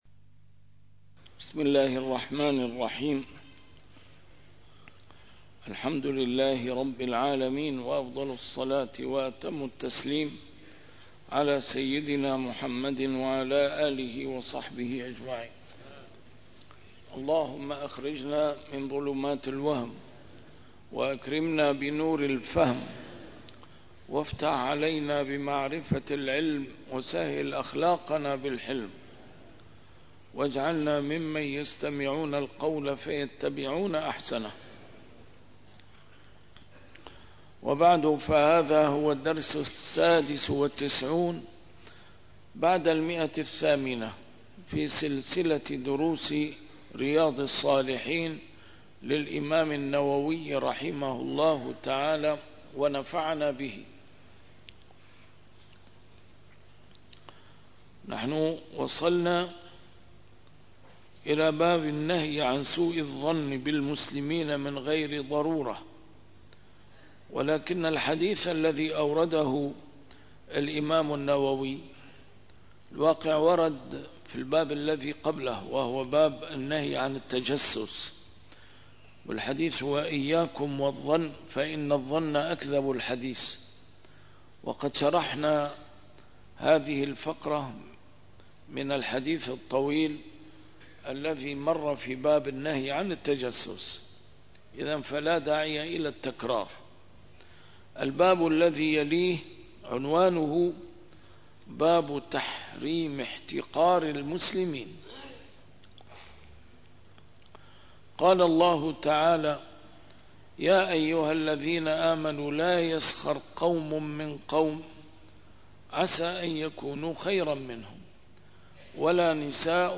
A MARTYR SCHOLAR: IMAM MUHAMMAD SAEED RAMADAN AL-BOUTI - الدروس العلمية - شرح كتاب رياض الصالحين - 896- شرح رياض الصالحين: تحريم احتقار المسلمين